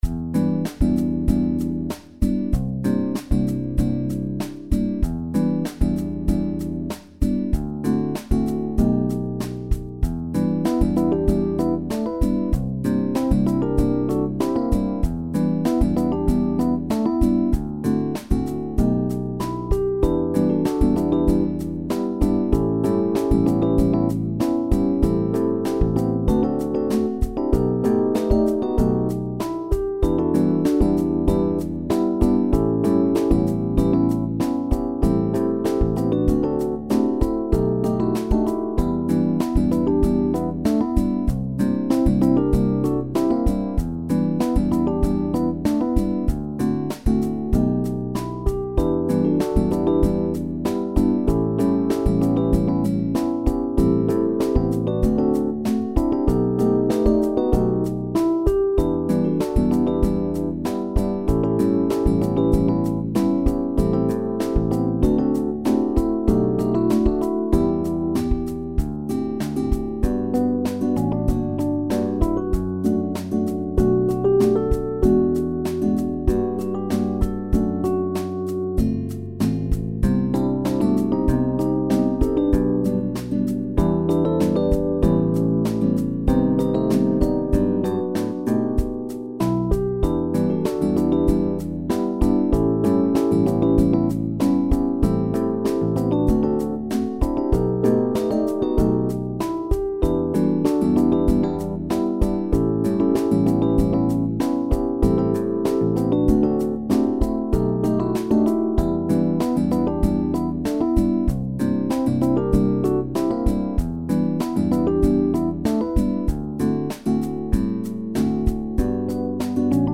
SSAA mit Klavier